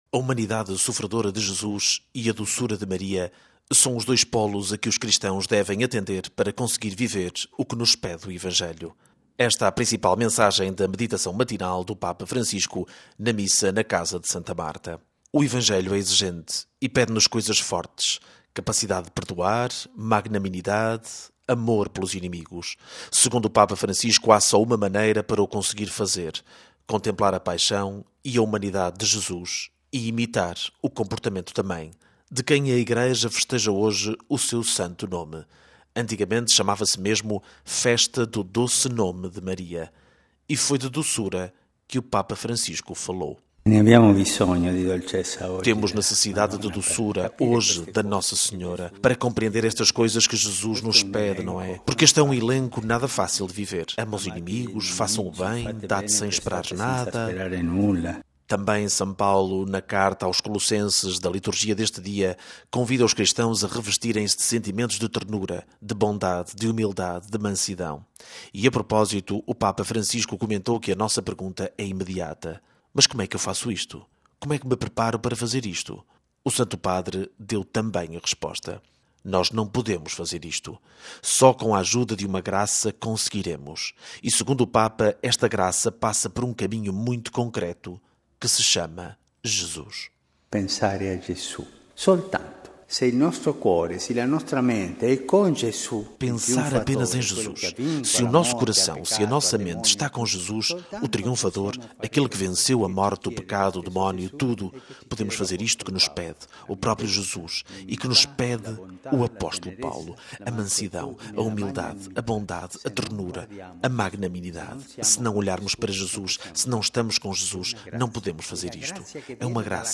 A humanidade sofredora de Jesus e a doçura de Maria são os dois pólos a que os cristãos devem atender para conseguir viver o que nos pede o Evangelho. Esta a principal mensagem da meditação matinal do Papa Francisco na missa na Casa de Santa Marta.